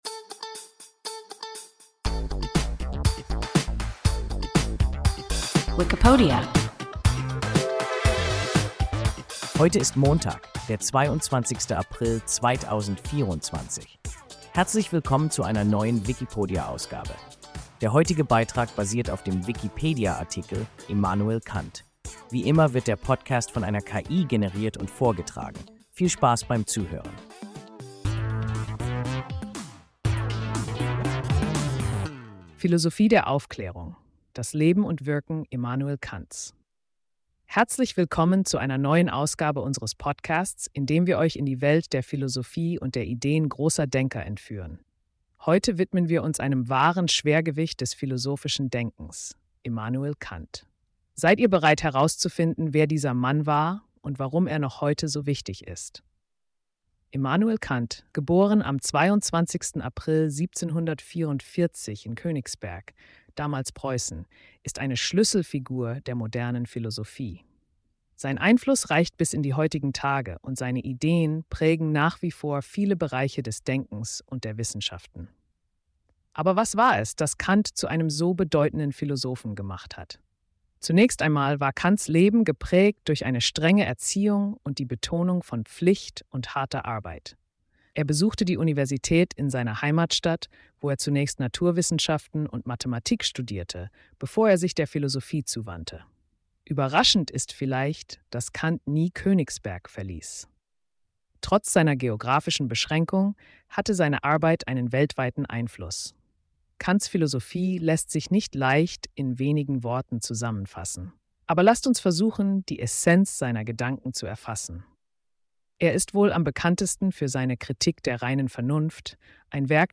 Immanuel Kant – WIKIPODIA – ein KI Podcast